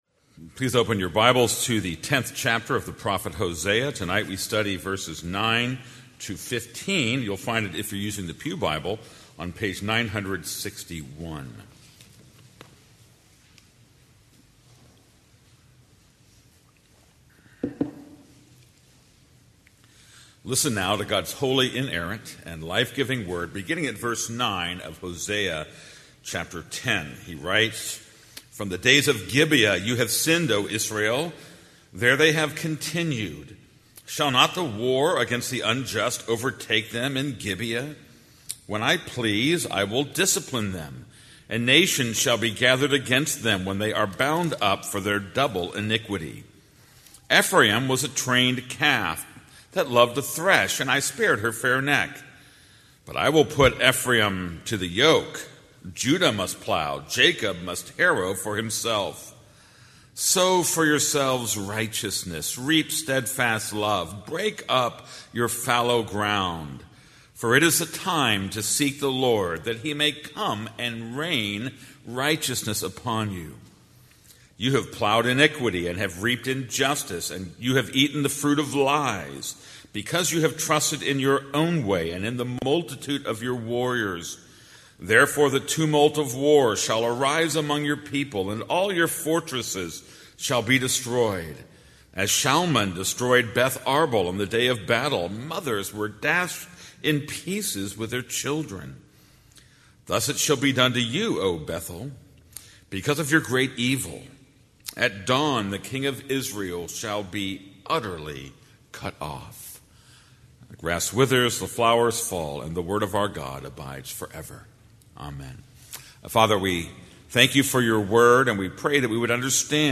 This is a sermon on Hosea 10:9-15.